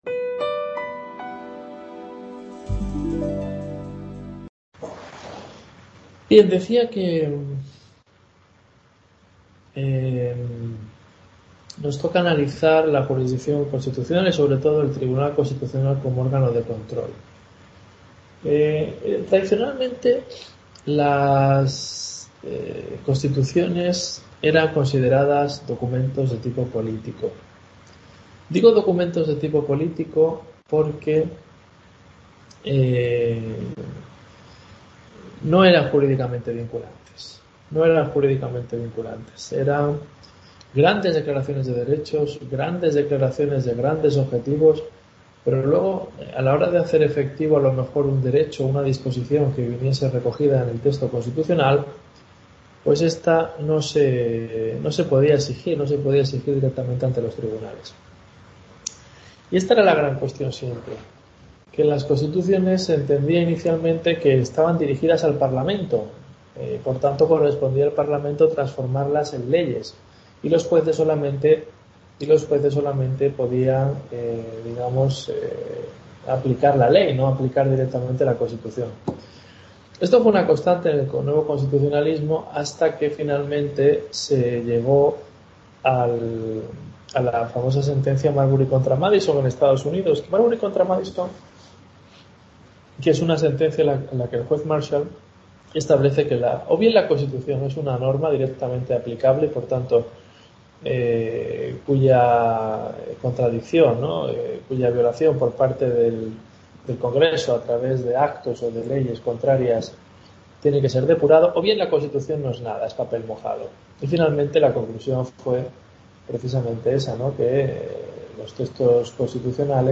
Tutoría UNED Portugalete